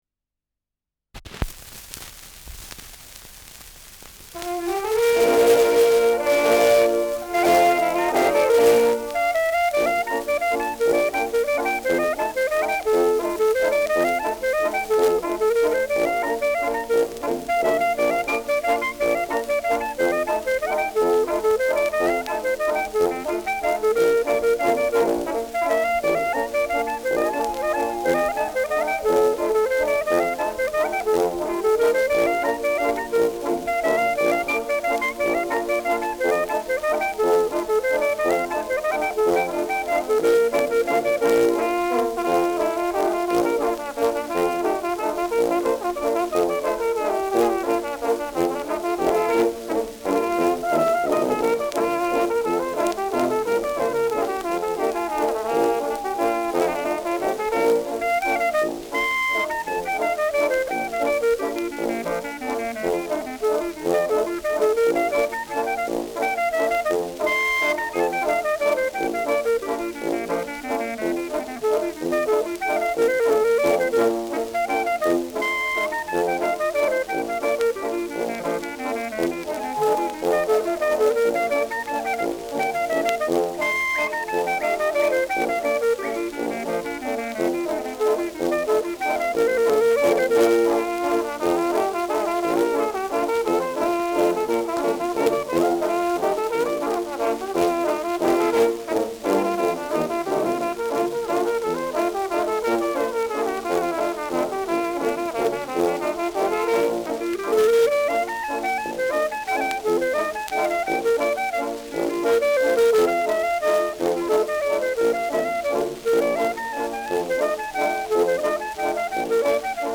Schellackplatte
leichtes Rauschen
Dachauer Bauernkapelle (Interpretation)